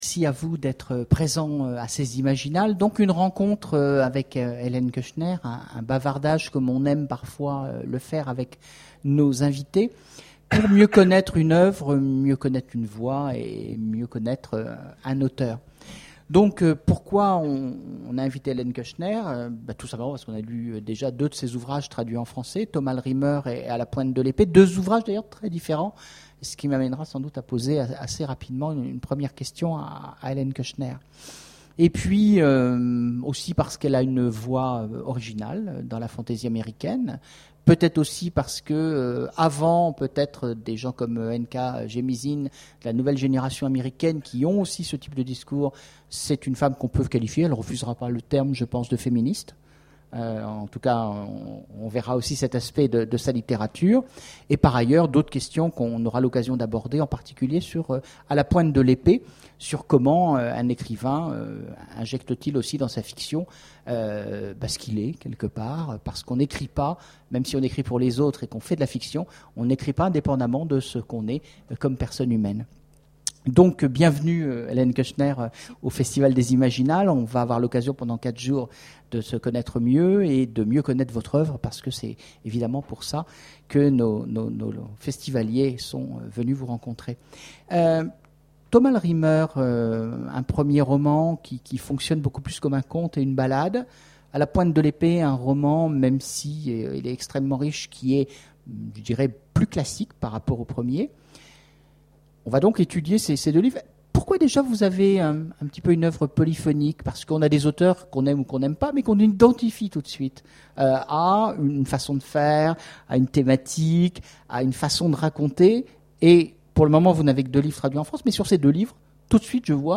Imaginales 2011 : conférence avec Ellen Kushner
Aujourd'hui une conférence avec Ellen Kushner au moment de sa venue aux Imaginales en 2011.